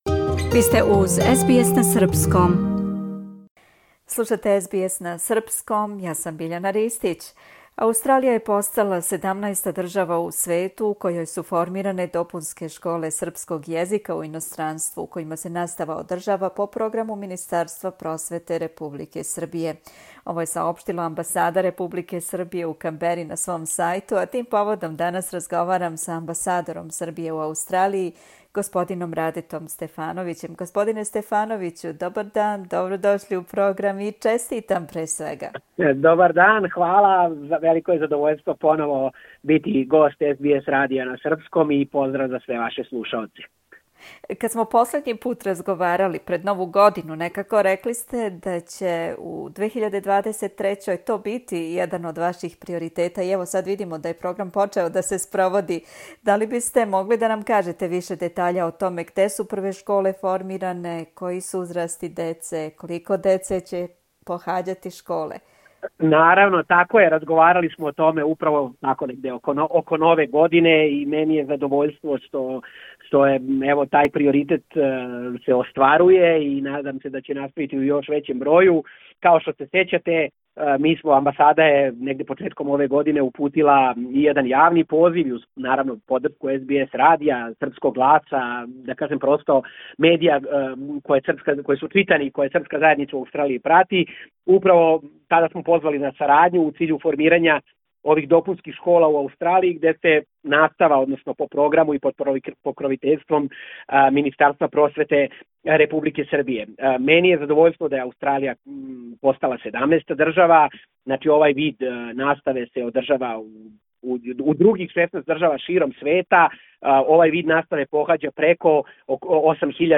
Амбасада Републике Србије у Аустралији саопштила је да је Аустралија постала 17. држава у свету у којој су формиране допунске школе српског језика у иностранству у којима се настава одржава по програму Министарства просвете Републике Србије. Тим поводом разговарали смо са амбасадором Србије у Канбери господином Радетом Стефановићем.